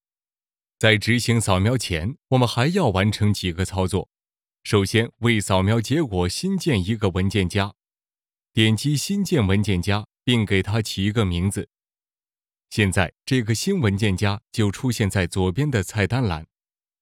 Chinese_Male_050VoiceArtist_4Hours_High_Quality_Voice_Dataset